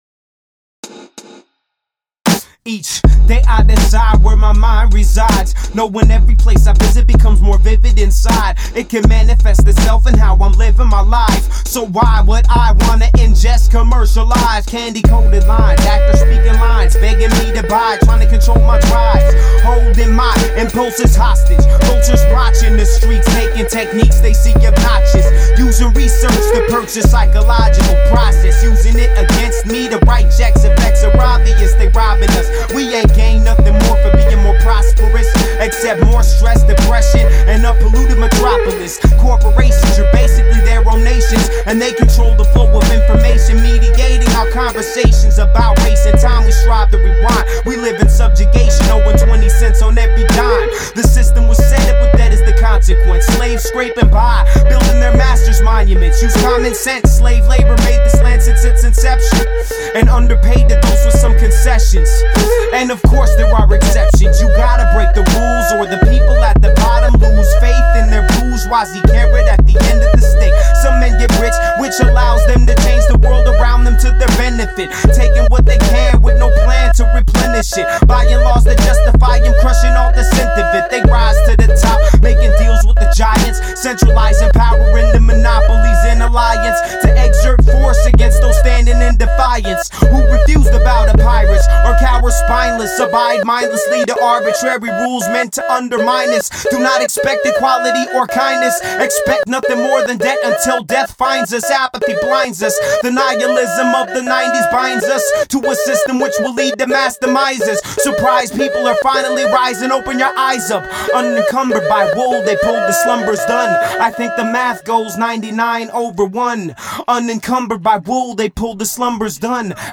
local hip-hop artists